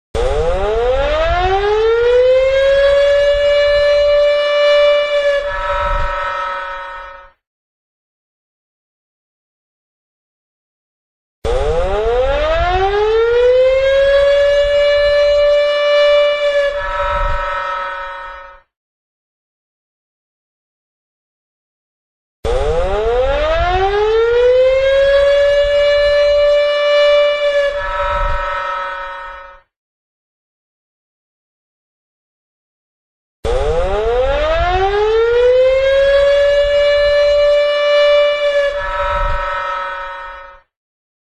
災害時などには昼夜問わず大きなサイレン音が鳴り、付近住民の皆さんには大変ご迷惑をおかけいたしますが、ご理解ご協力をお願いします。
サイレン信号の種類
火災
火災の発生を知らせるほか、緊急に消防職団員の招集が必要な時に吹鳴します。   5秒－休止6秒－5秒－休止6秒－5秒－休止6秒－5秒